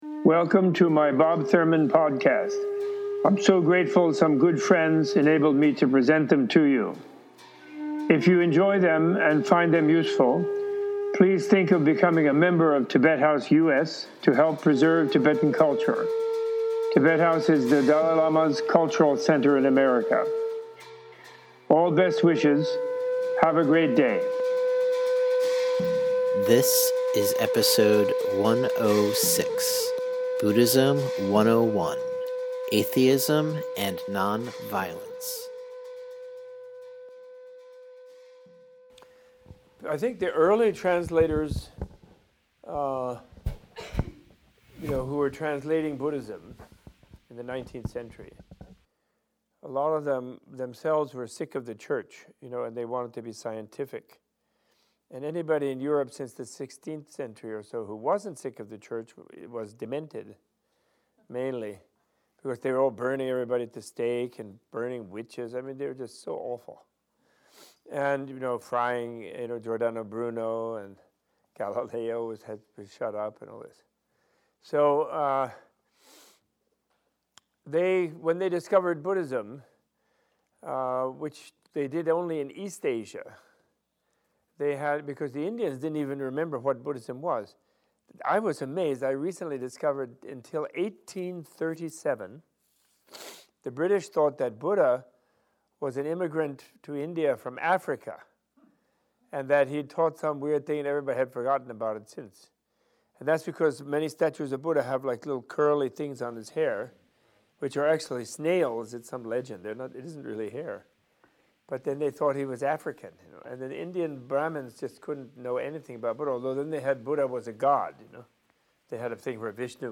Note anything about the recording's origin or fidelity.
Recorded at the 2008 Hiking in the Catskills Retreat at Menla, Phoenicia, New York.